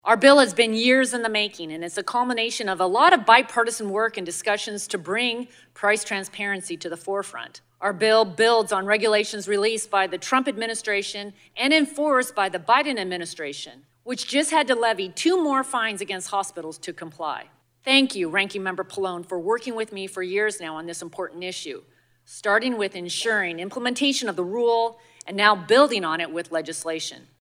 WASHINGTON, DC – Eastern Washington Congresswoman Cathy McMorris Rodgers (WA-05) delivered opening remarks today at the Health Subcommittee hearing on the need to improve healthcare price transparency and increase competition to help lower costs for patients nationwide.